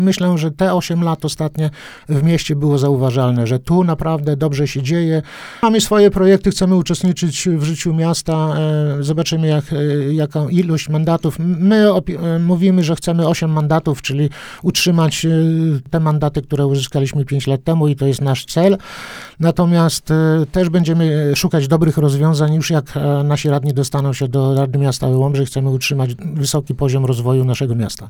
Liczymy na 8 mandatów w wyborach do rady miejskiej Łomży – mówił na naszej antenie